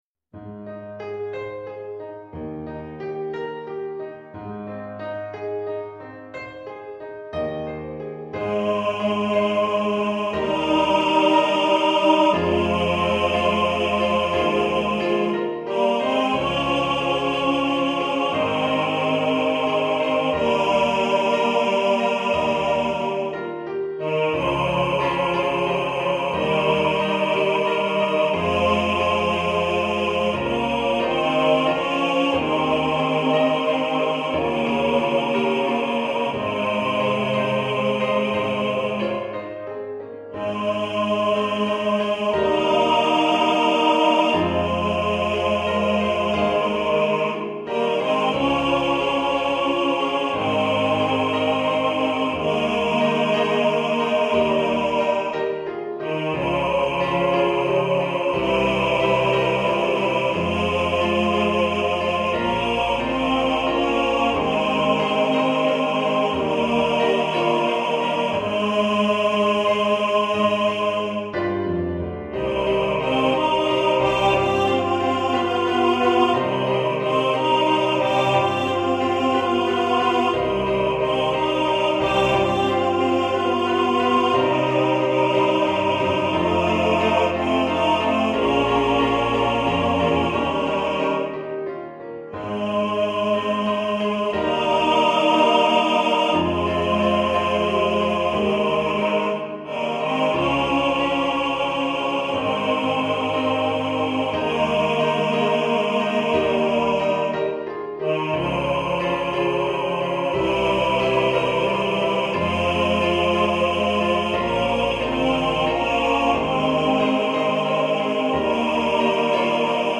3-stimmige Ausgabe für Männerchor